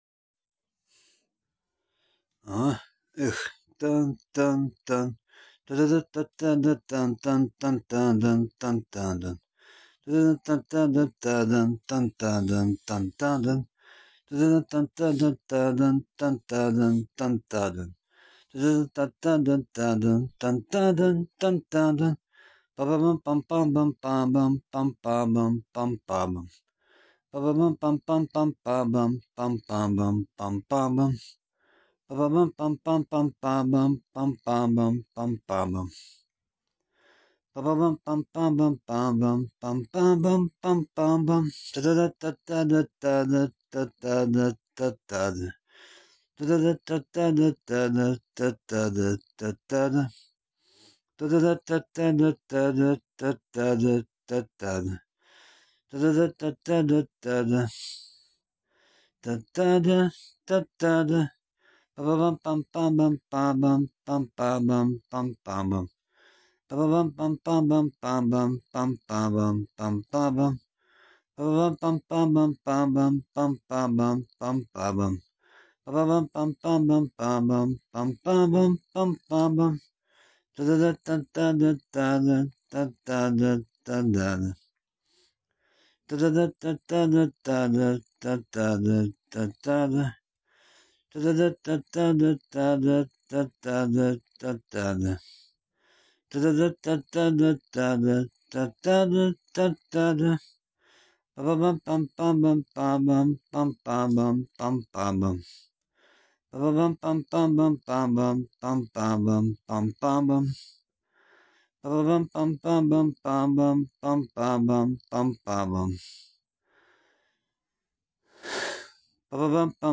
Извините меня , пожалуйста, за вот такие вот вопросы , но эта самая без вокальная электронная звуковая дорожка , композиция или же мелодия звучала на таких радиостанциях , как Dynamite - FM , Energy или же Europa Plus , - примерно где - то в 2010 - ом году ! . . Своим звучанием или же своим мотивом она немножко напоминает : - Da Hool - Meet Here At The Love Parade , - но только вот она слегка побыстрее , повеселее покрасивее или же помелодичнее !
А выглядело всё это дело примерно вот так вот : - то есть как уж смог , - то так уж и напел : -